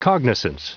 Prononciation du mot cognizance en anglais (fichier audio)
Prononciation du mot : cognizance